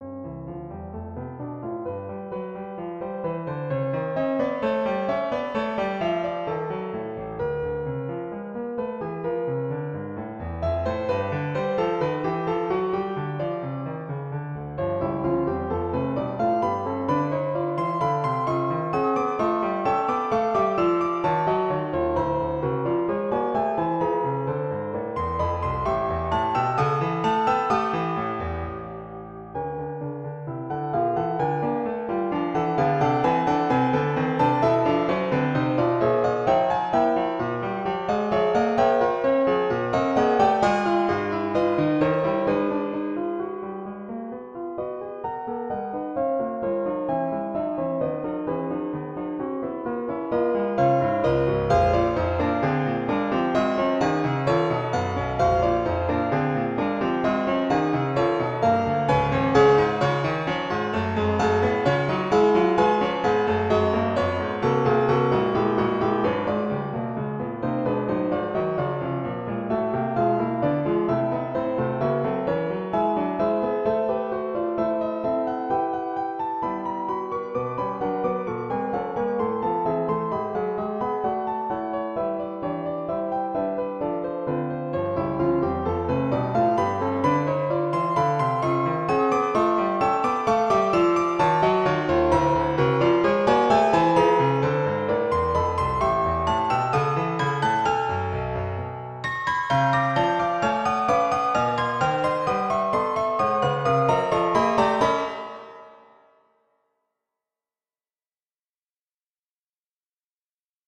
Great playing, great dynamics, just great allthe way around.